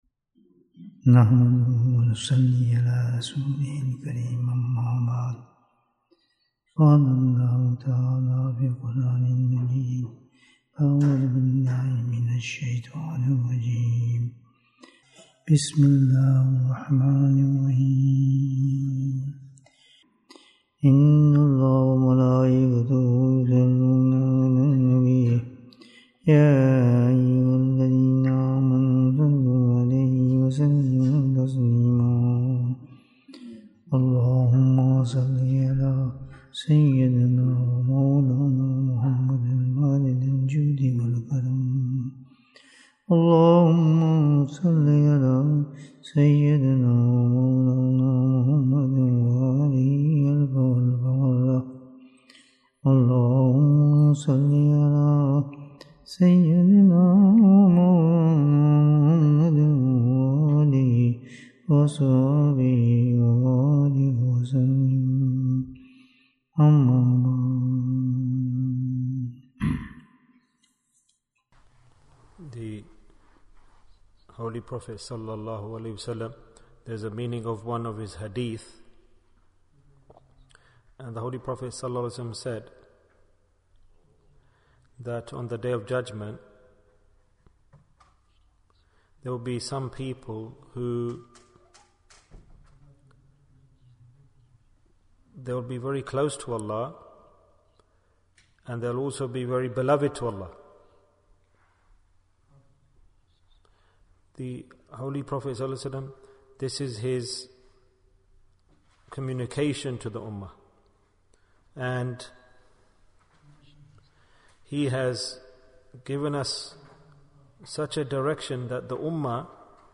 Who Will be Dear to Allah in Qiyamah? Bayan, 69 minutes13th October, 2022